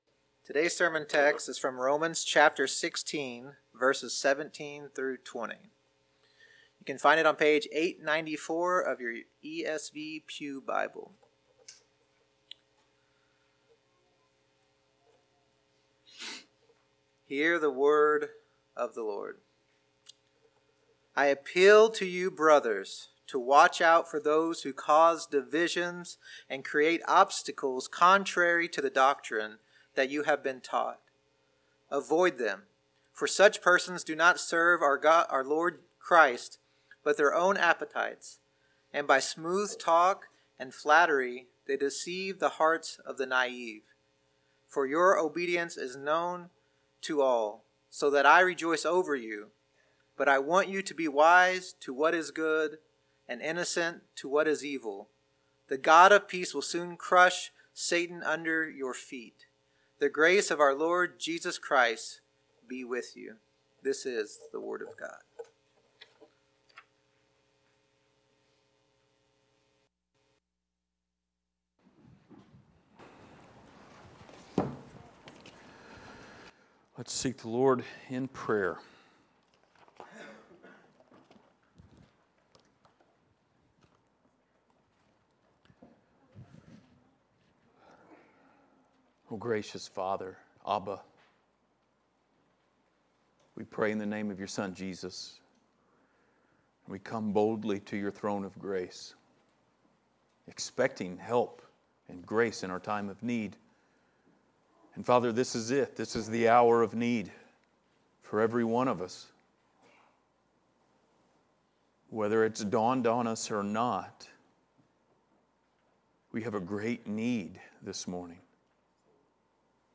Passage: Romans 16:17-20 Service Type: Sunday Morning